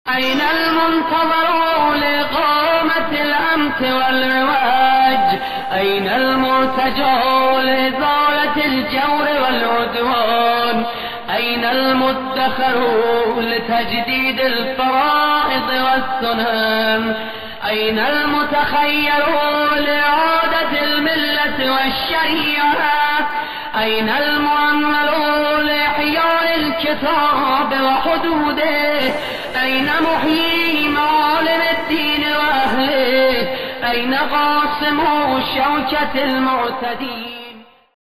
by reciter